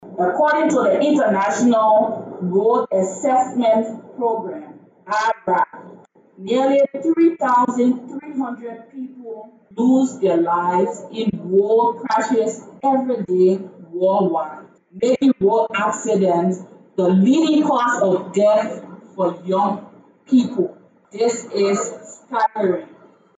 On October 24, the Head of the National Road Fund of Liberia (NRF), Mrs. Joseta Neufville-Wento, claimed during a live interview on Spoon online TV that nearly 3,300 lives are lost in road crashes daily worldwide, making road accidents the leading cause of death for young people.